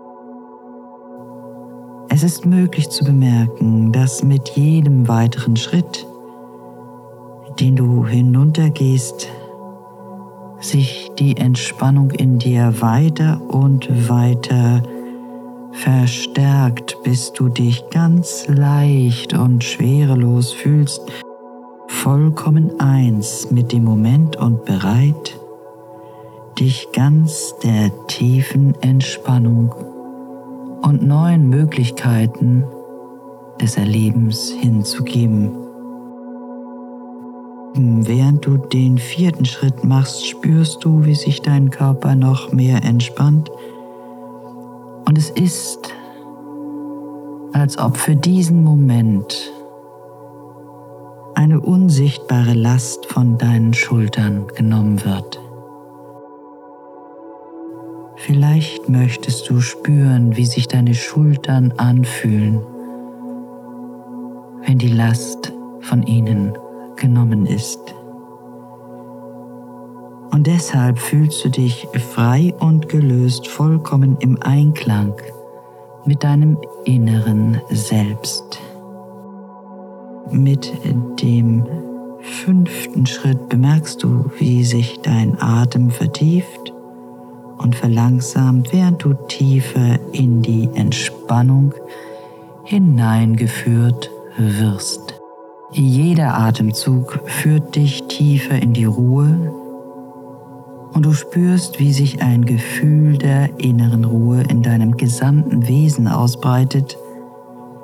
Dies ist eine Meditation, die speziell für Menschen entwickelt wurde, die unter dem Reizdarmsyndrom (IBS) leiden. Es handelt sich um eine Meditation, die darauf abzielt, die emotionale Beziehung zum Darm zu verändern und Stress abzubauen.
Details: Musik: Ja; Gesamtlänge: ca. 38 Minuten.